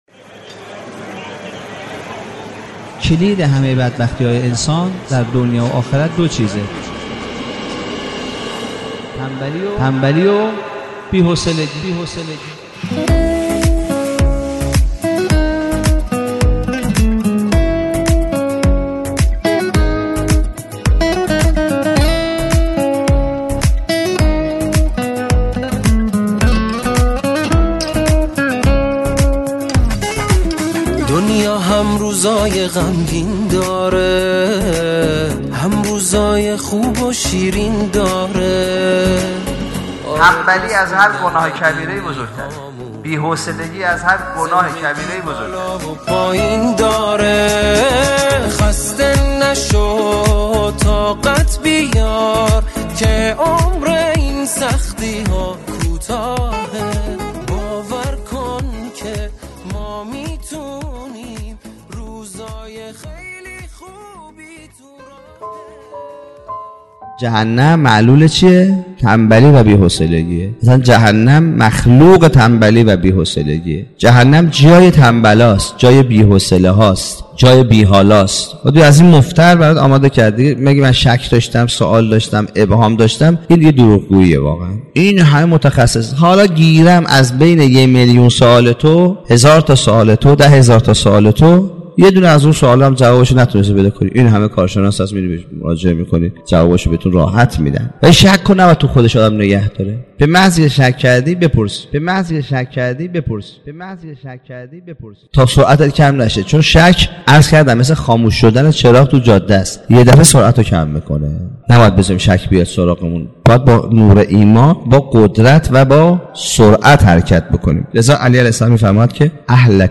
سخنرانی کوتاه